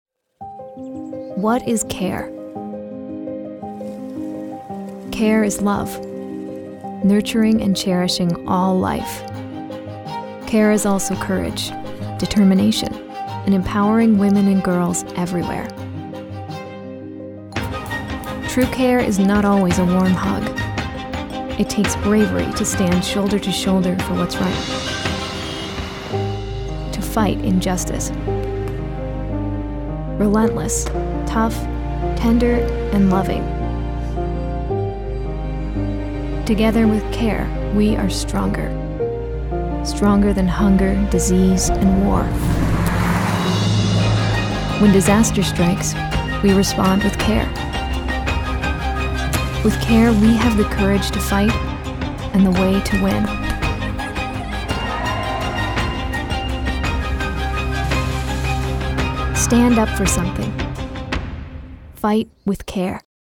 Female Voice Over, Dan Wachs Talent Agency.
Current, Modern, Young Mom, Heartfelt.
Warm, Friendly